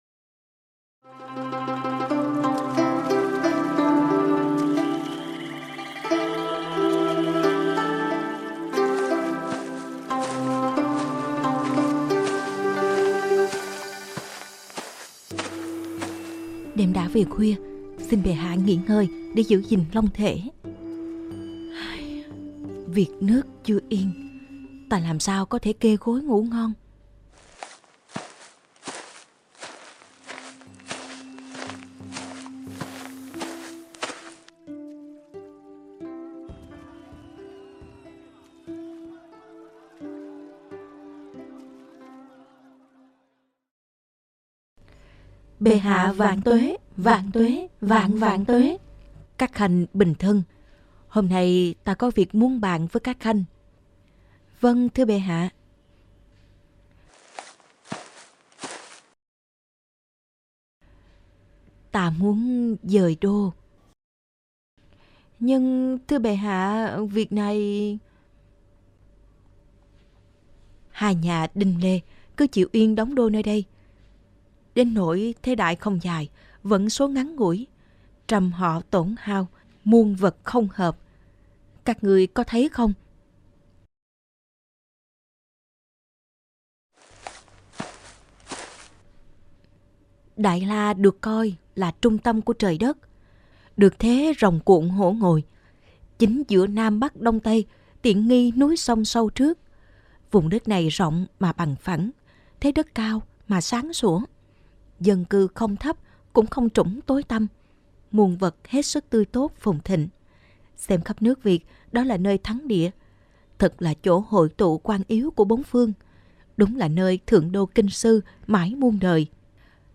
Sách nói | Lý Thái Tổ dời đô từ Hoa Lư về Thăng Long